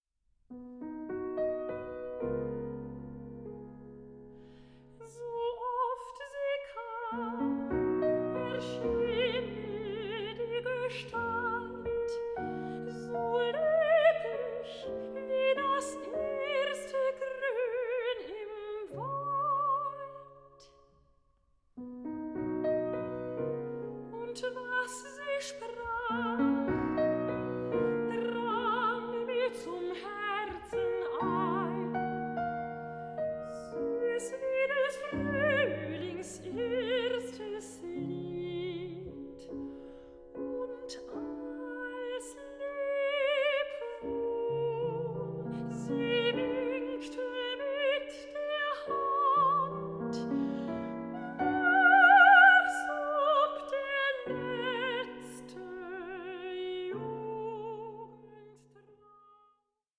soprano
pianist